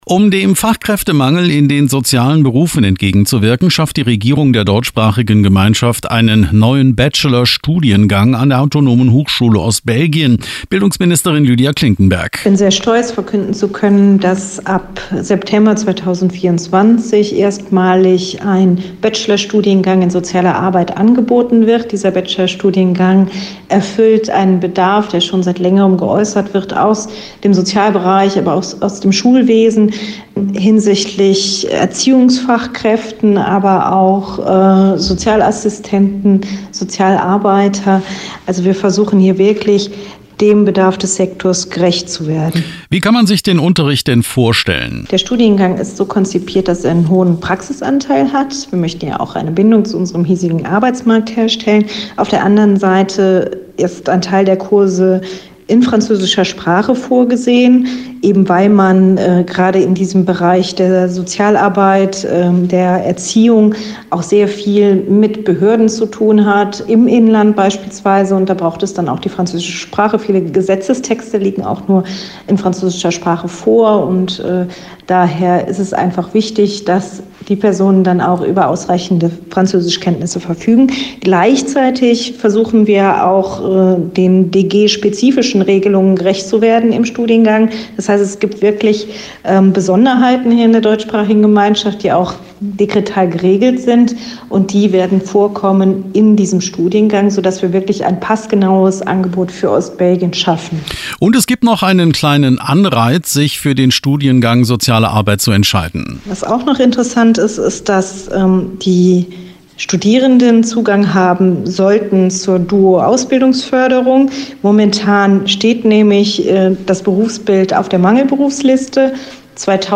Studiengang-Soziale-Arbeit-Interview.mp3